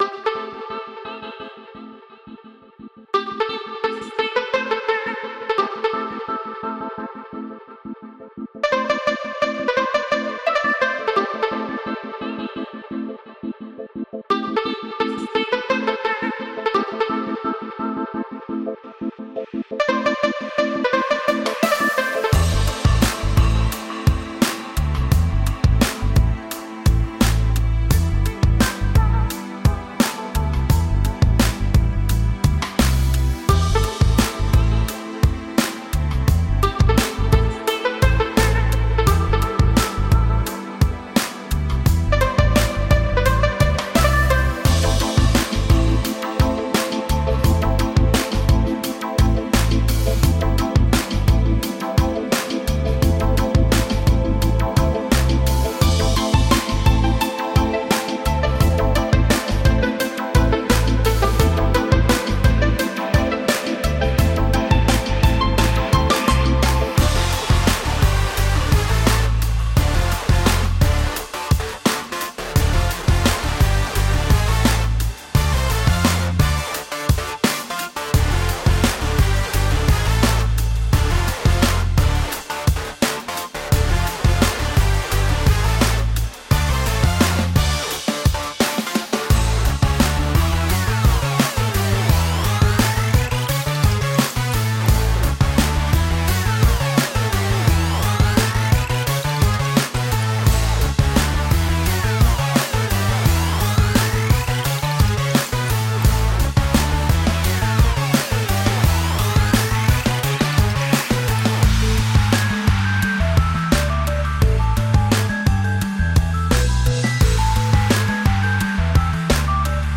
Tagged as: Electronica, Downtempo, Instrumental, Downtempo